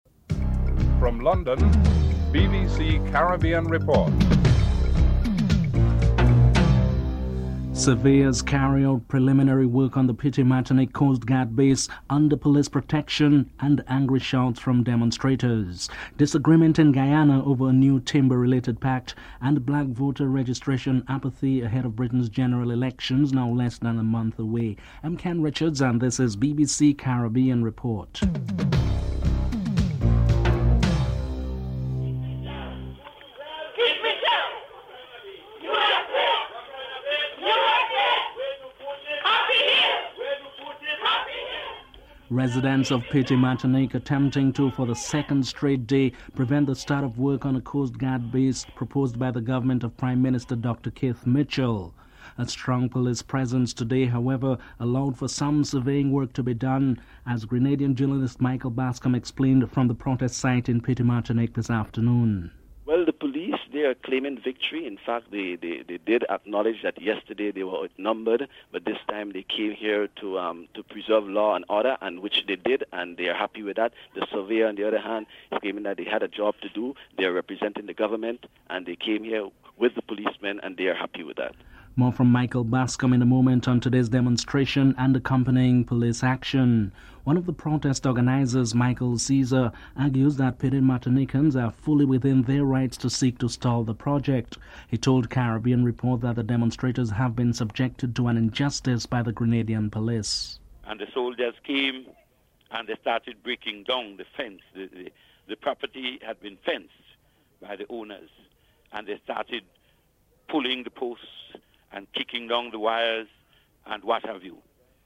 Surveyors carry out preliminary work on the Petite Martinique Coast Guard base under police protection and angry shouts from demonstrators.